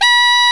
Index of /90_sSampleCDs/Roland LCDP07 Super Sax/SAX_Alto Short/SAX_Pop Alto
SAX A 4 S.wav